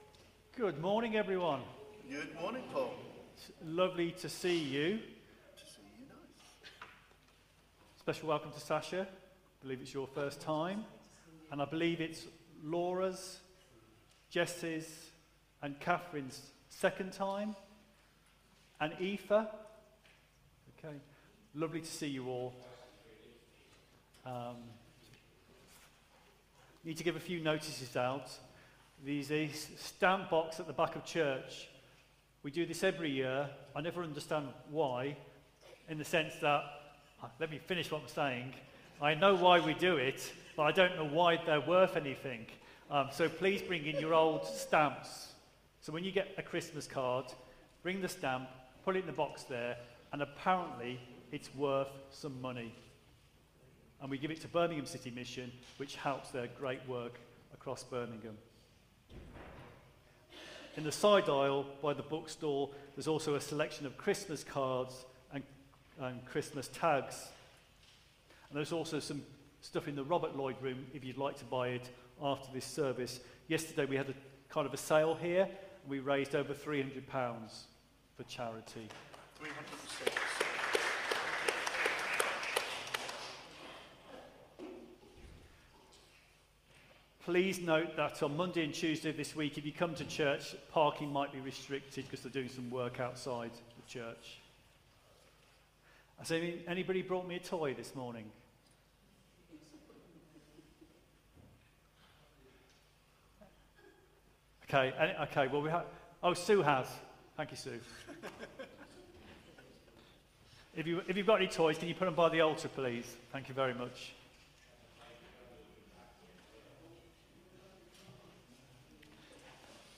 Media for Contemporary Worship on Sun 24th Nov 2024 11:00 Speaker